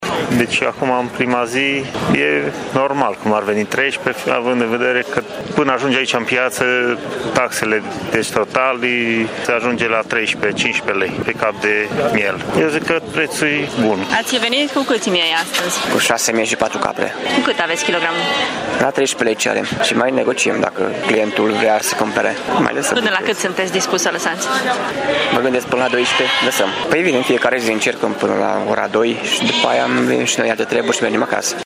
Patru producători mureșeni au venit, astăzi, în Piața Cuza Vodă din Tîrgu-Mureș cu câteva zeci de miei de vânzare.